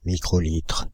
Ääntäminen
Synonyymit lambda Ääntäminen France (Île-de-France): IPA: /mi.kʁo.litʁ/ Haettu sana löytyi näillä lähdekielillä: ranska Käännöksiä ei löytynyt valitulle kohdekielelle.